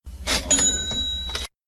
moneyCollect.ogg